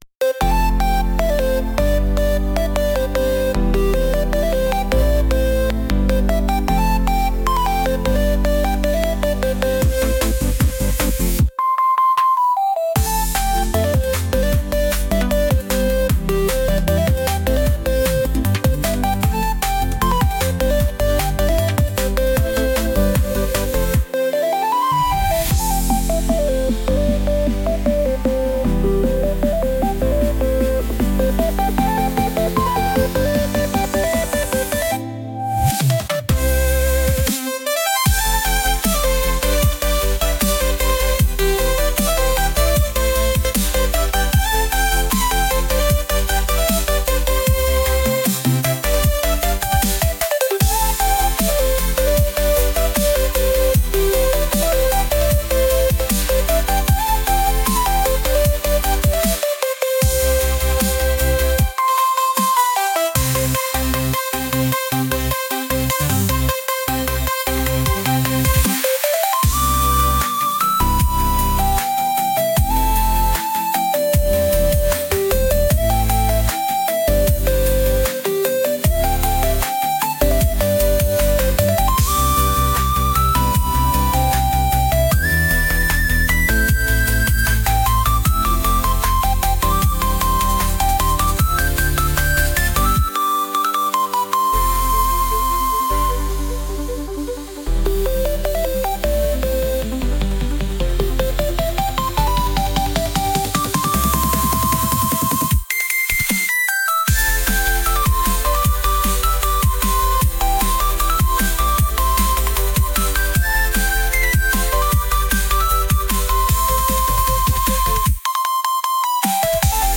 雪だるま式に恋心が膨らんでいくような曲です。